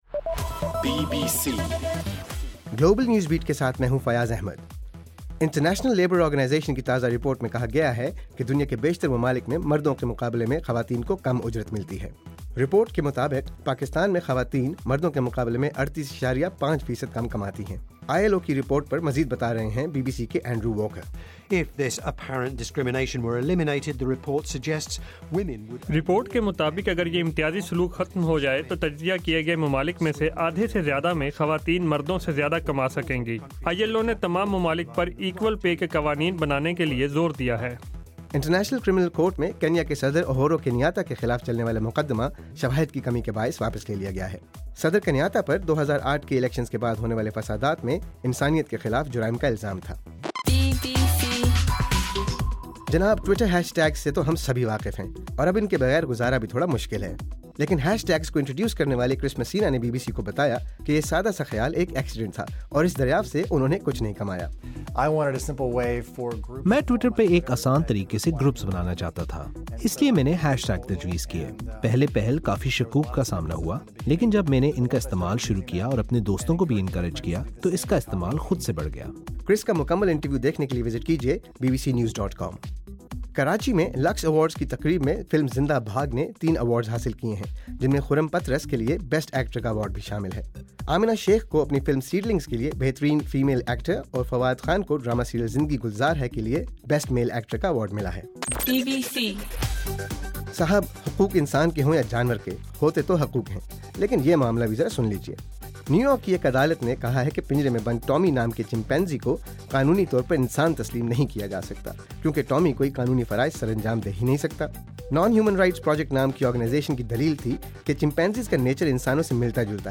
دسمبر 5: رات 8 بجے کا گلوبل نیوز بیٹ بُلیٹن